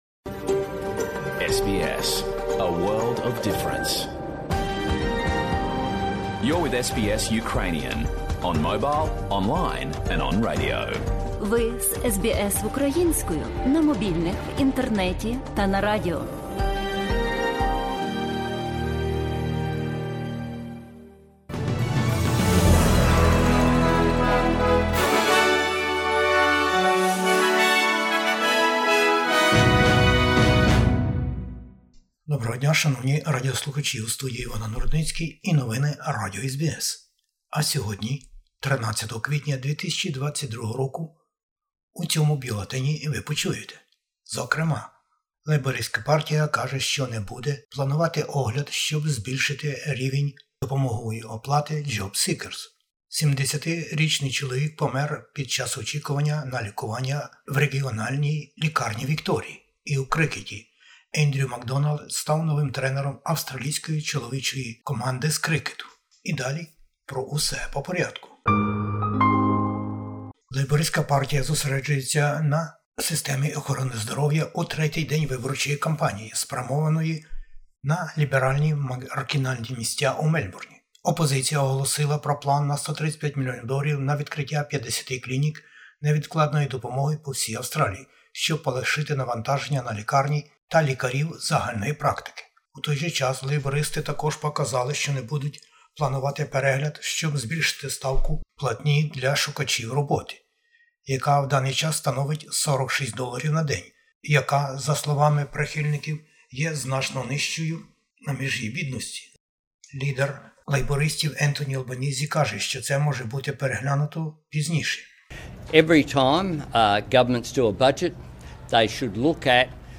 Бюлетень SBS новин українською. Передвиборчі баталії напередодні Федеральних виборів в Австралії. Війна в Україні та реакції США і світу. 70-річний чоловік помер, чекаючи на допомогу лікарів у лікарні. Новий тренер австралійських крікетерів.